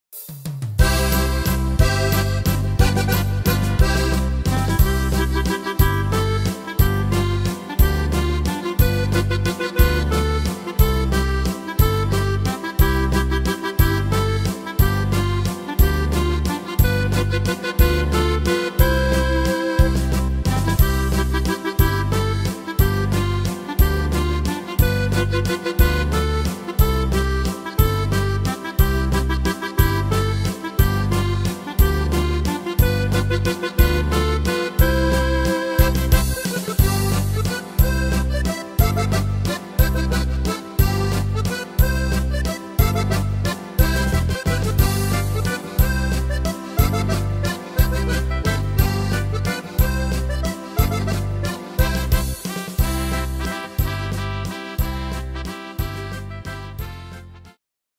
Tempo: 180 / Tonart: C-Dur